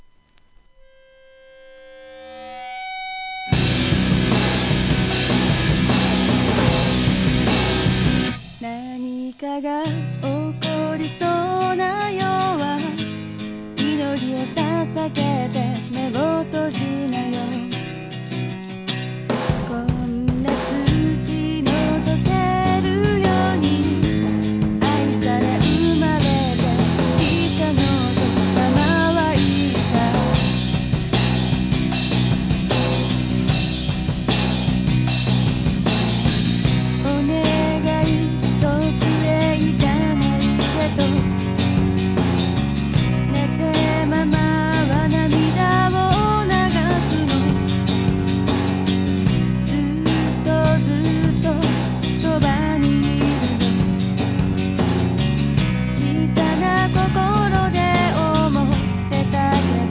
POP,歌謡曲、和モノ